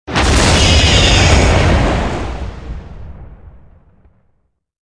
med explo 2.wav